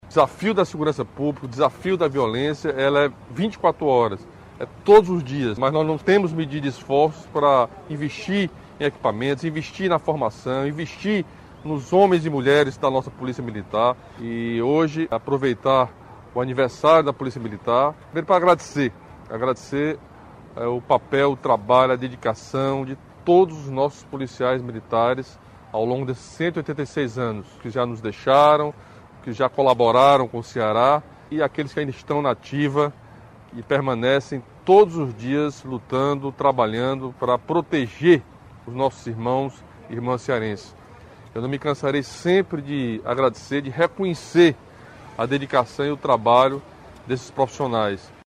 Camilo Santana ressaltou o papel dos homens e mulheres que fazem a Polícia Militar para garantir segurança para a população de Fortaleza no dia do aniversário de fundação da corporação.